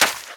High Quality Footsteps
STEPS Sand, Run 06.wav